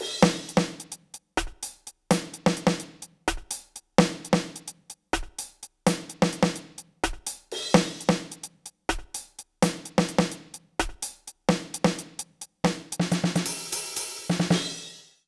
British ROCK Loop 124BPM (NO KICK).wav